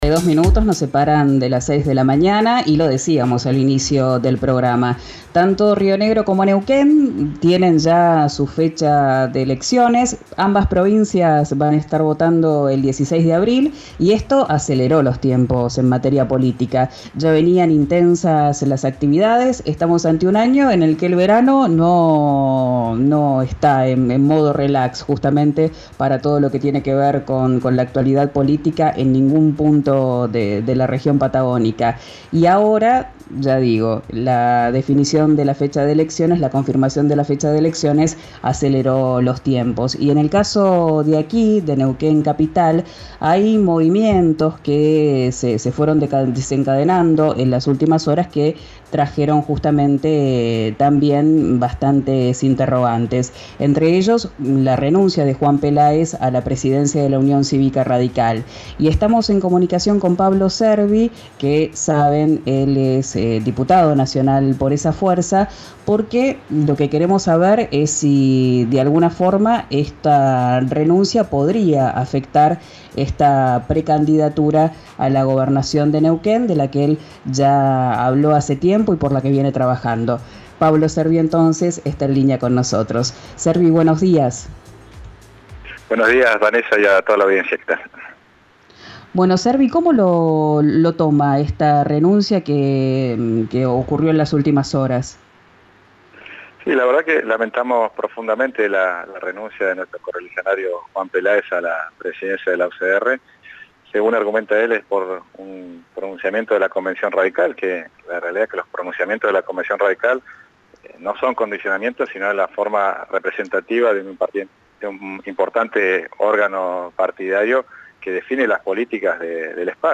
El diputado nacional por Evalución Radical mantiene firme su candidatura a gobernador de Neuquén. En diálogo con RÍO NEGRO RADIO contó que aún no pudo hablar con el concejal que aspira a ser intendente de la capital.